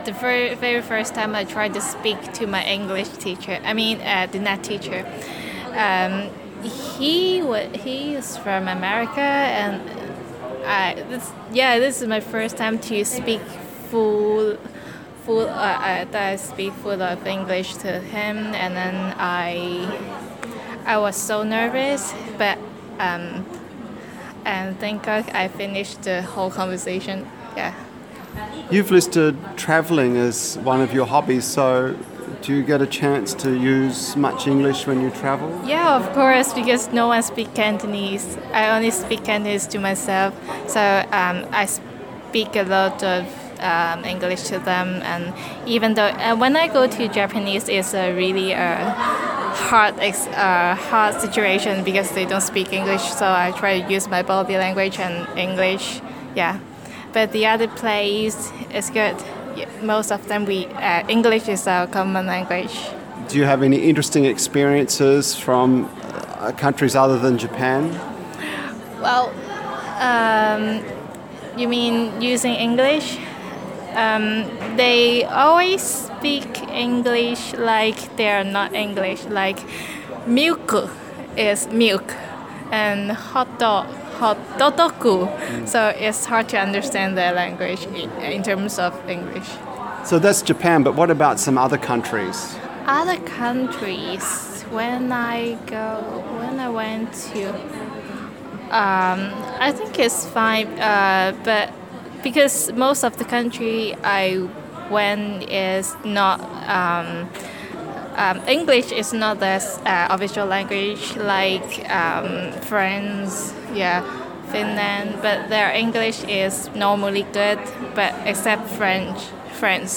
A Social Science major recalls the fear of chatting with an American NET for the first time. She uses English when travelling and studying. She reads journals.